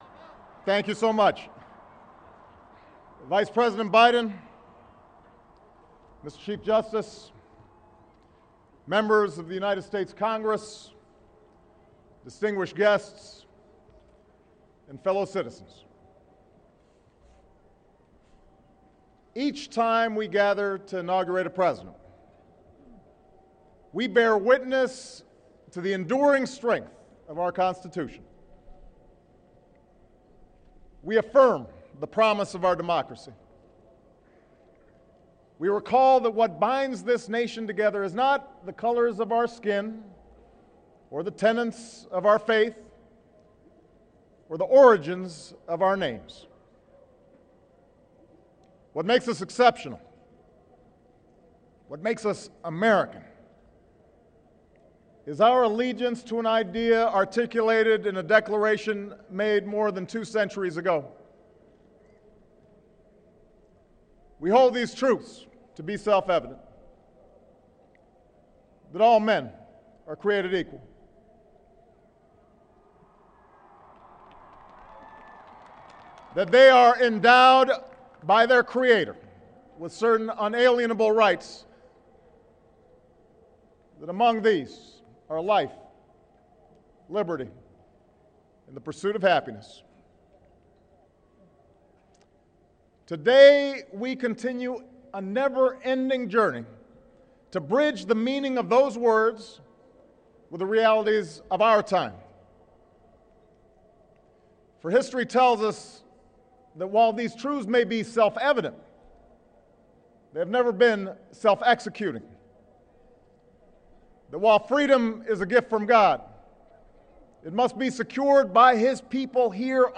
奧巴馬總統2013年1月21日就職演說全文(英語原聲)